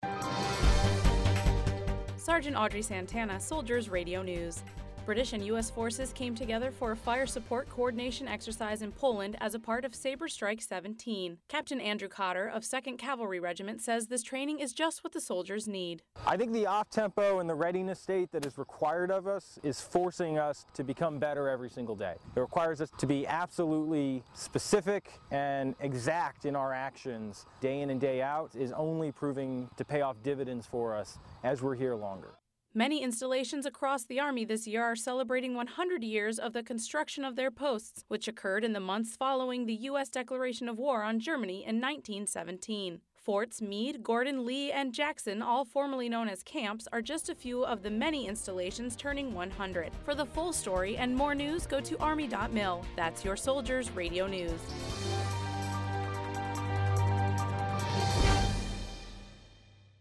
Polandsoldiers raido newsSaber Strike 17100 years oldsoldiertrainingexerciseArmy